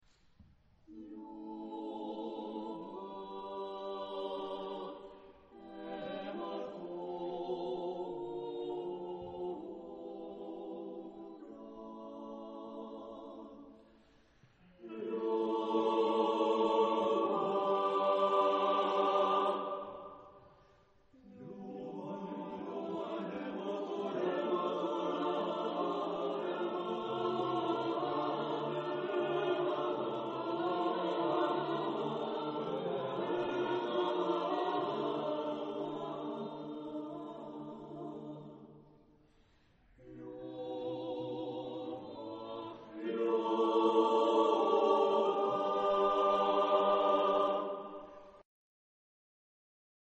Género/Estilo/Forma: Profano ; Madrigal ; contemporáneo
Tipo de formación coral: SSATBB  (6 voces Coro mixto )